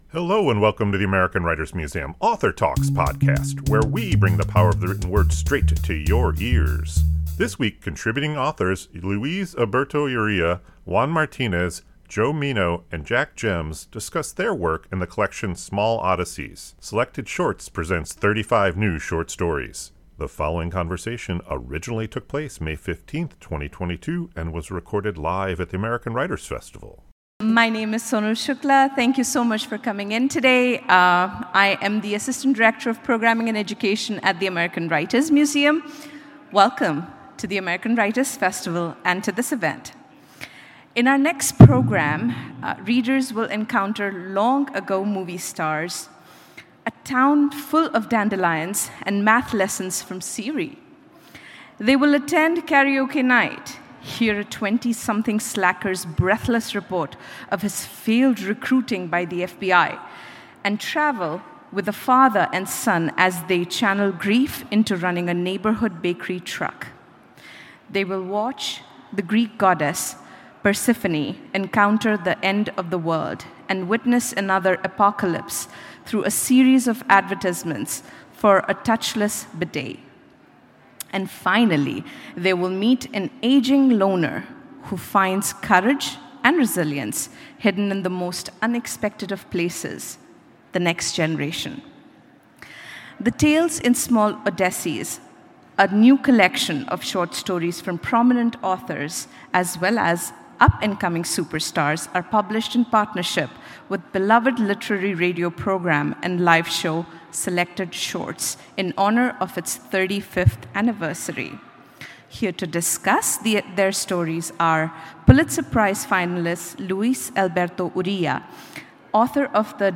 This conversation originally took place May 15, 2022 and was recorded live at the American Writers Festival.